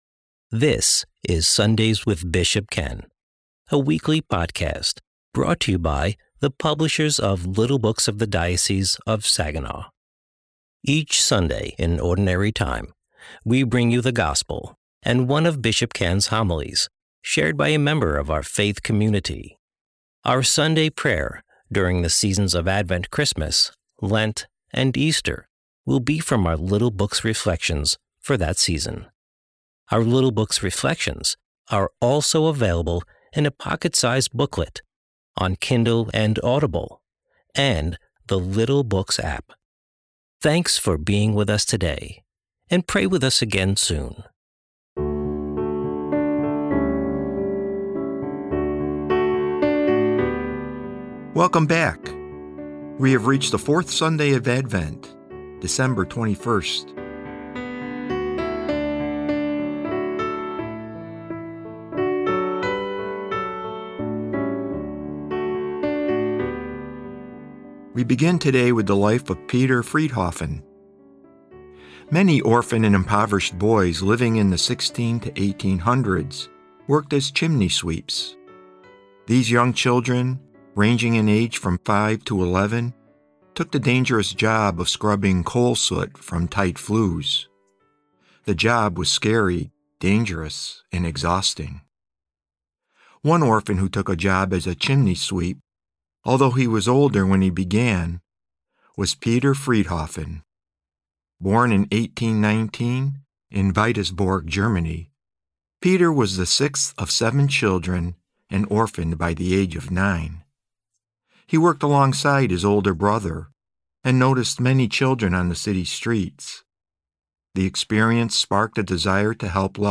Today's episode of Sunday's with Bishop Ken is the Fourth Sunday of Advent's reading from The Little Blue Book: Advent and Christmas 2025.